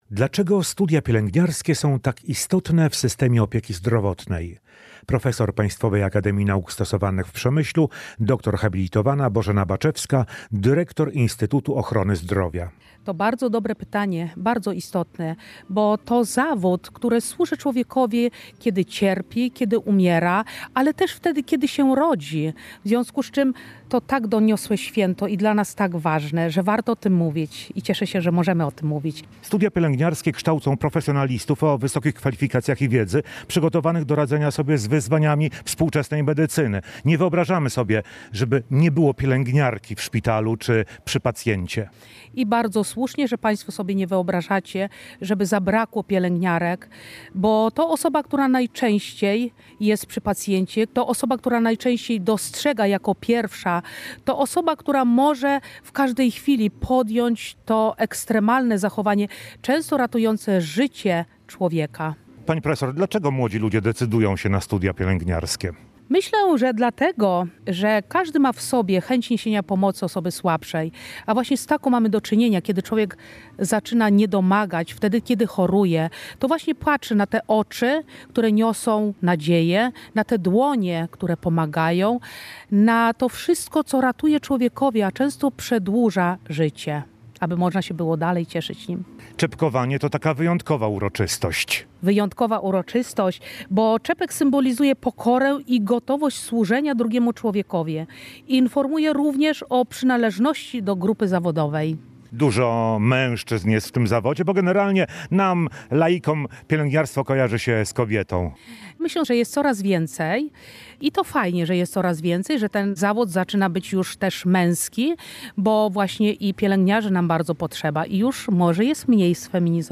Podczas uroczystości odegrano hymn pielęgniarek, a absolwenci kierunku złożyli uroczyste ślubowanie.